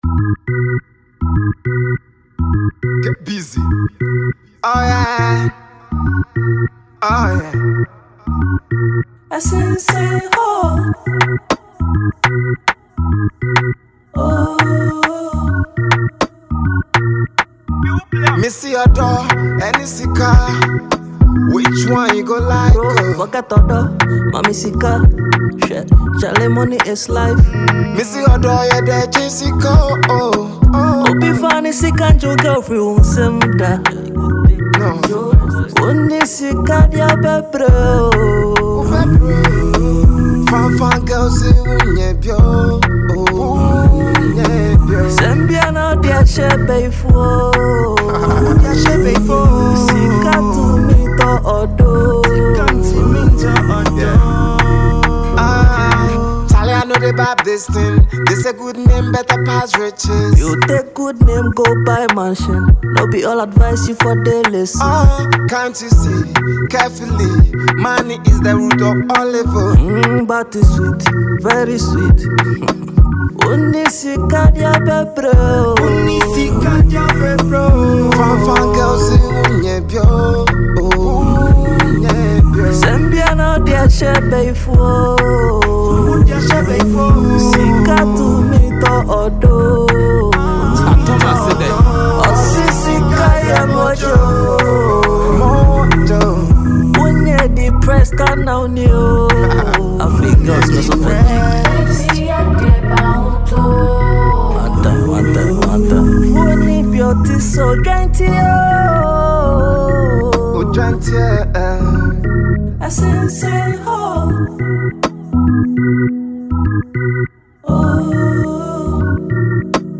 a Ghanaian highlife singer